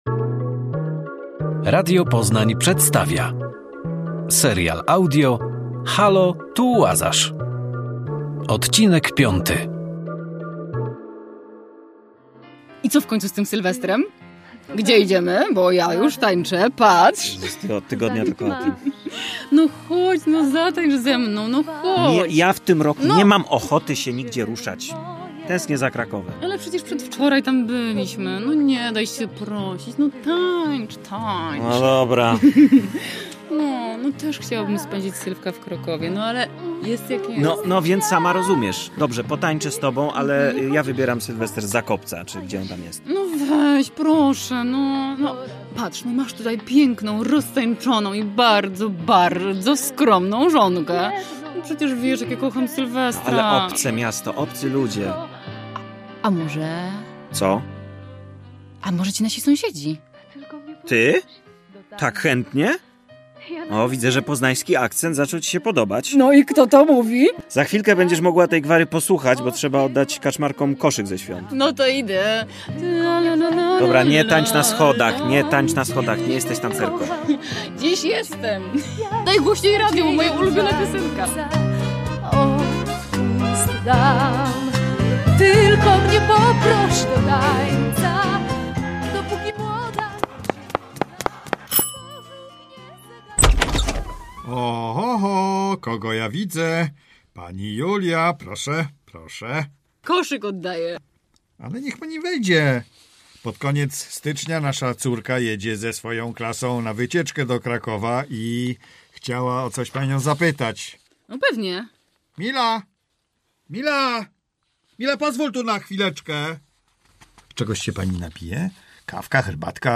KRASNOLUDEK U KUPCA I Słuchowisko dla dzieci - 03.01.2026